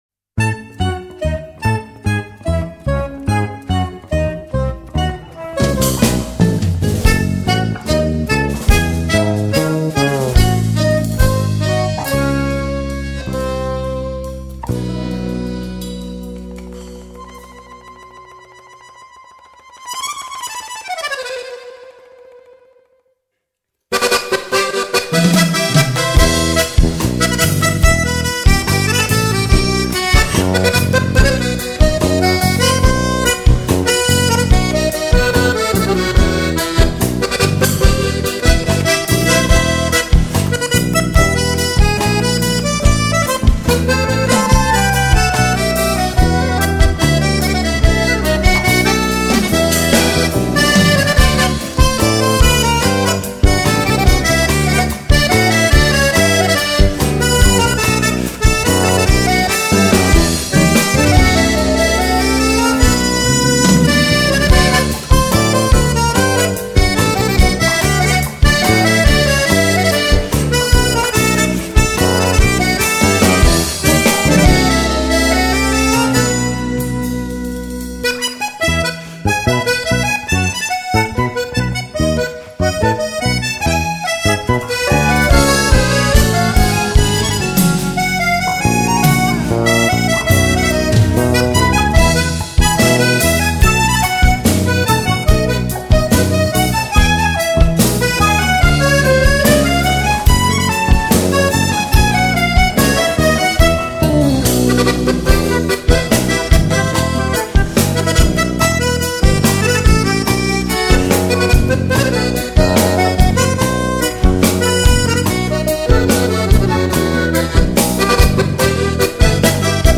专辑类型： 纯音乐
南美手风琴大师精彩演绎十五首风格独特的巴西音乐，旋律优美流畅，节奏轻快活泼。
美妙的探戈舞曲，情景交融，充满节奏色彩的美丽